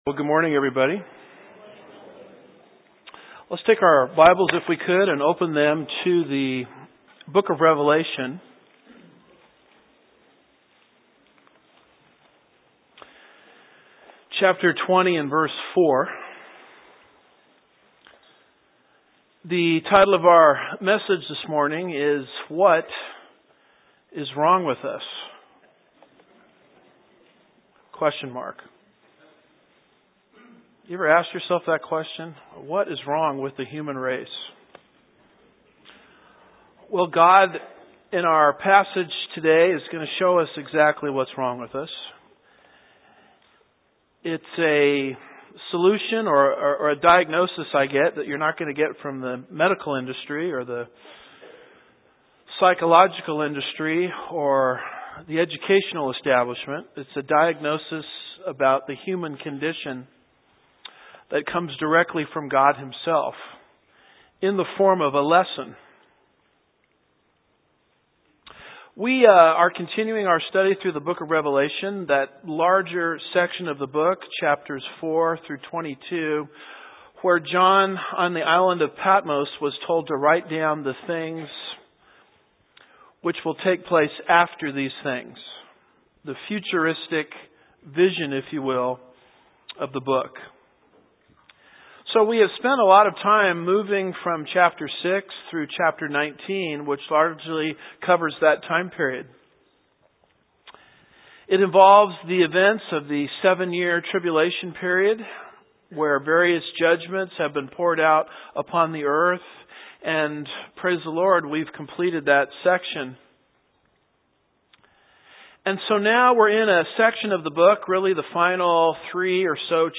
Part 1, Revelation 20:4b-6 Lesson 65 Good morning everybody.